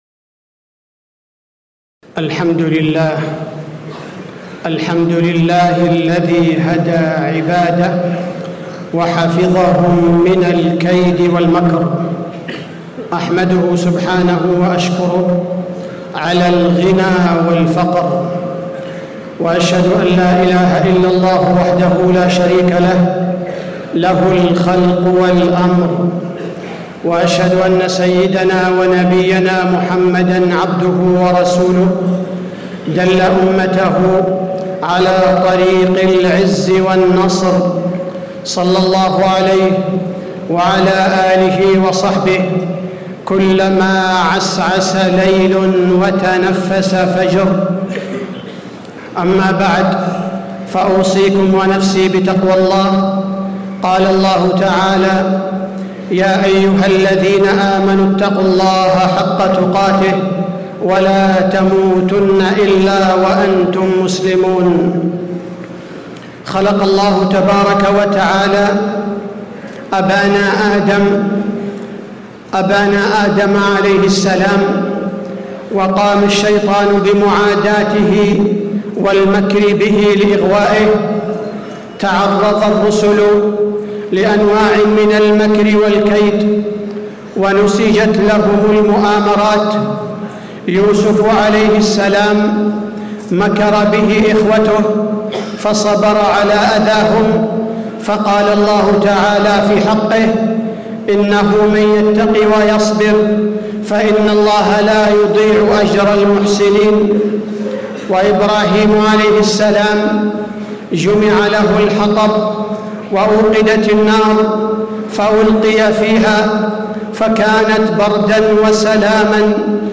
تاريخ النشر ٢٦ ذو الحجة ١٤٣٦ هـ المكان: المسجد النبوي الشيخ: فضيلة الشيخ عبدالباري الثبيتي فضيلة الشيخ عبدالباري الثبيتي صفات أهل المكر والخيانة